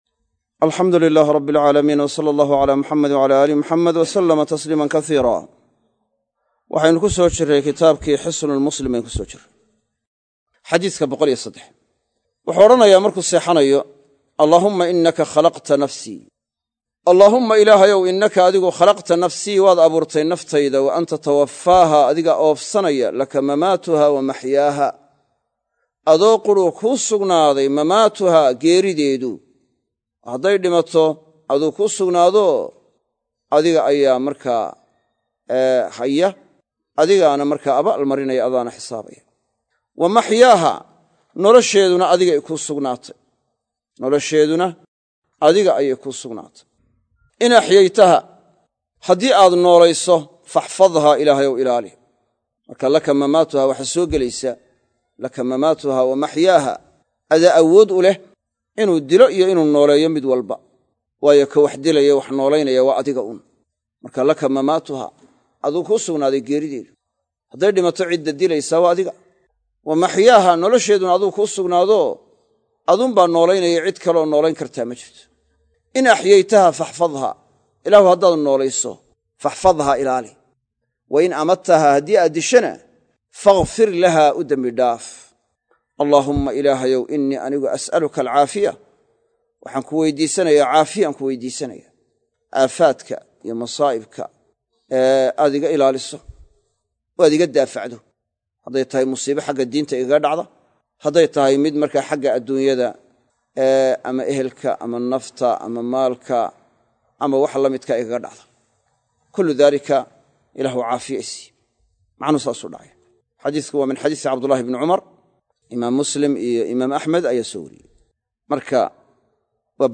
Xisnul-Muslim- Darsiga 12aad - Manhaj Online |